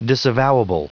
Prononciation du mot disavowable en anglais (fichier audio)
Prononciation du mot : disavowable